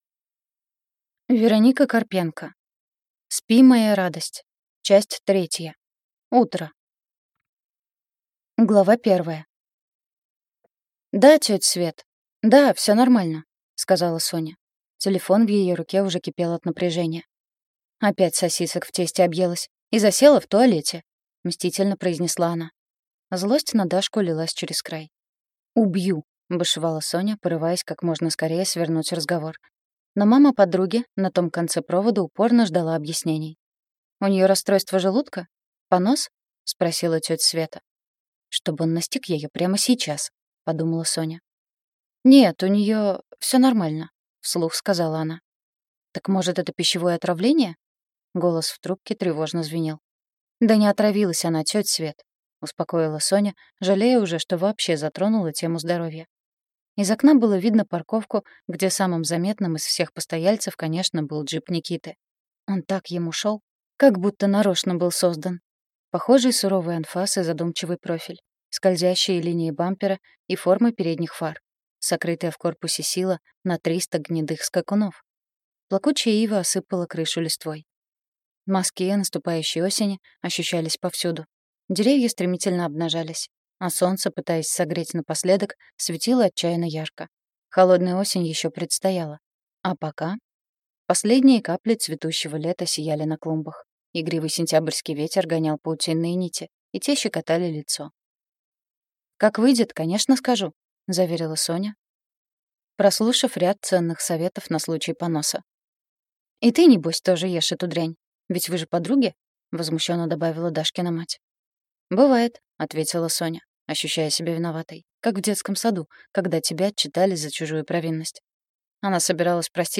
Аудиокнига Спи, моя радость. Часть 3. Утро | Библиотека аудиокниг